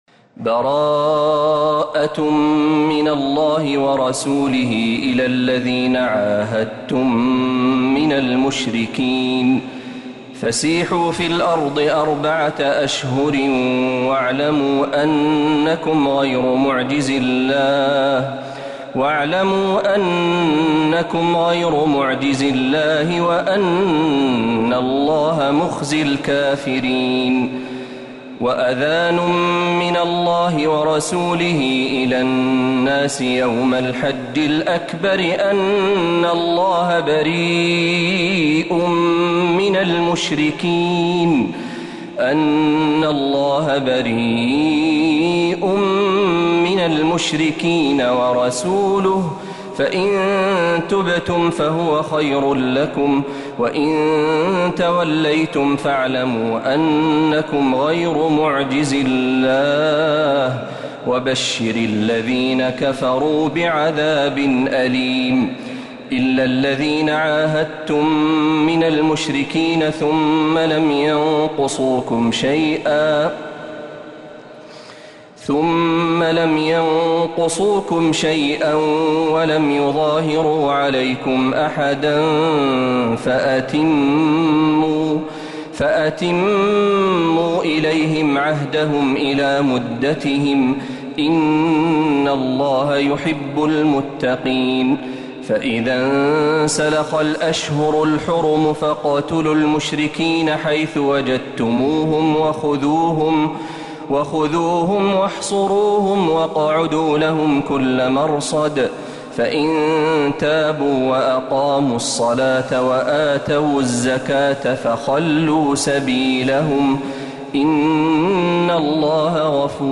سورة التوبة كاملة من الحرم النبوي